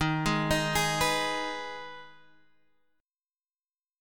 Ebsus4#5 Chord